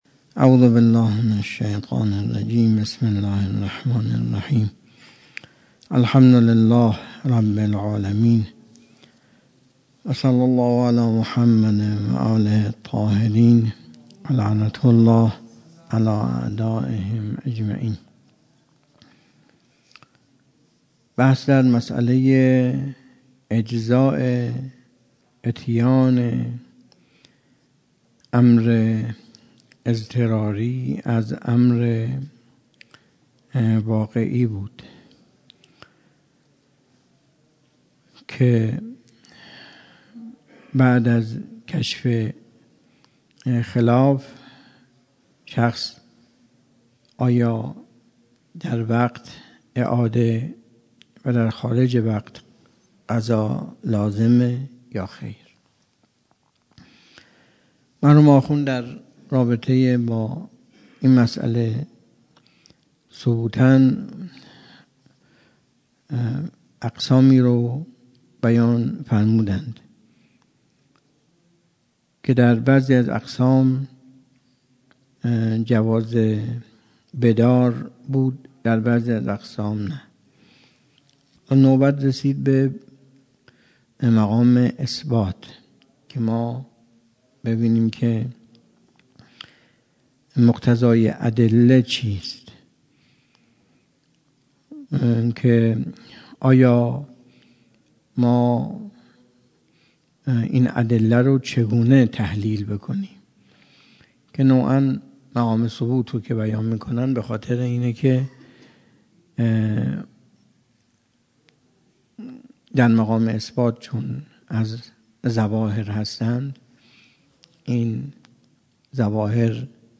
درس خارج اصول